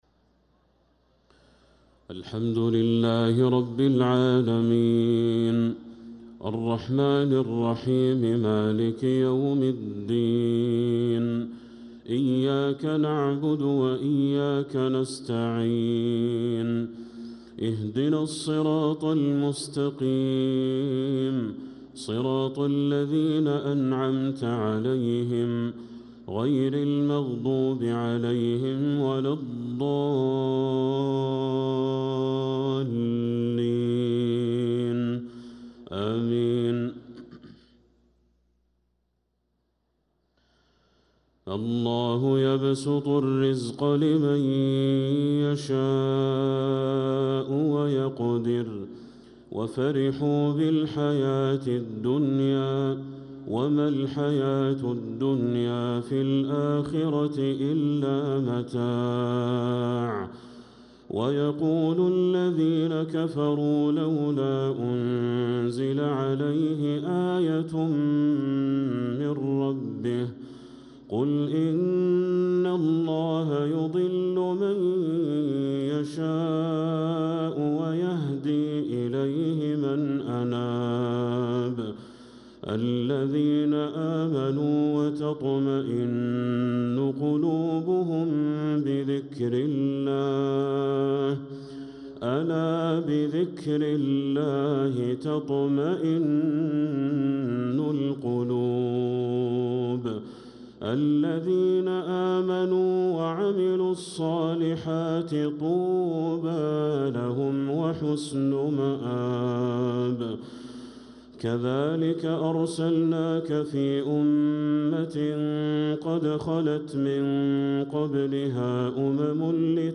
صلاة المغرب للقارئ بدر التركي 20 رجب 1446 هـ